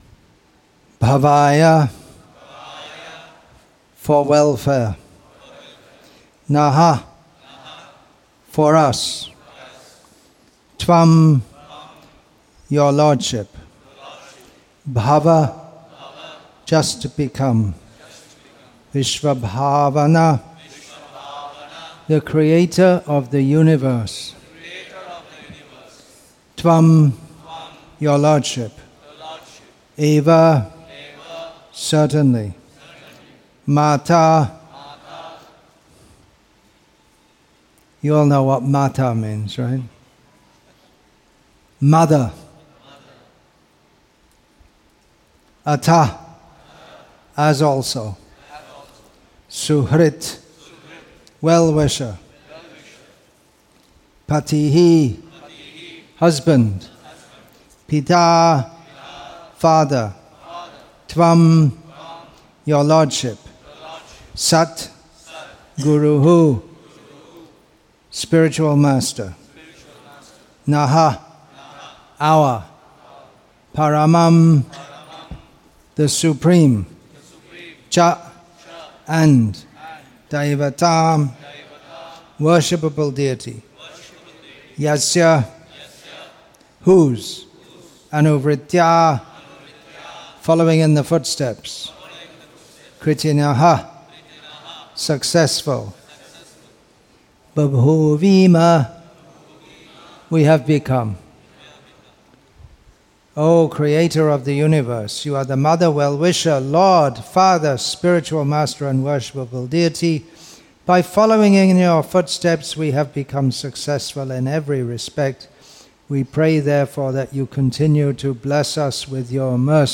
English with தமிழ் (Tamil) Translation; Chennai, Tamil Nadu , India Śrīmad-Bhāgavatam 1.11.7 Play Download Add To Playlist